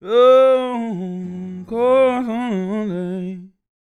MOANIN 073.wav